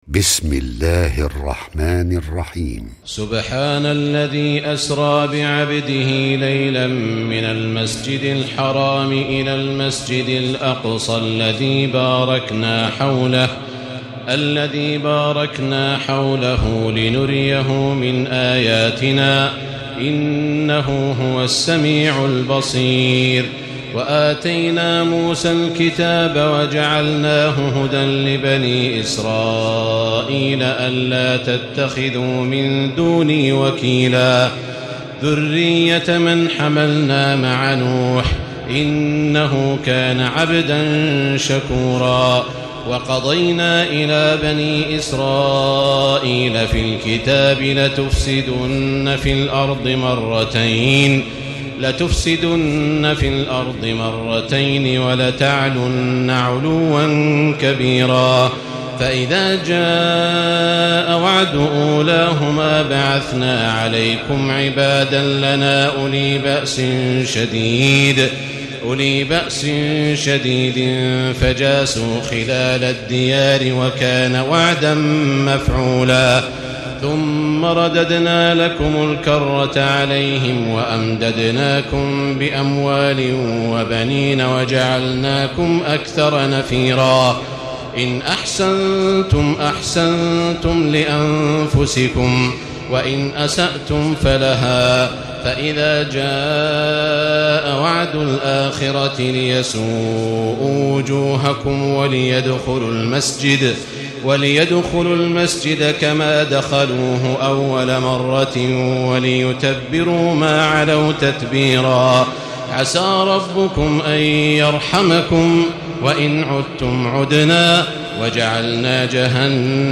تراويح الليلة الرابعة عشر رمضان 1436هـ من سورة الإسراء (1-98) Taraweeh 14 st night Ramadan 1436H from Surah Al-Israa > تراويح الحرم المكي عام 1436 🕋 > التراويح - تلاوات الحرمين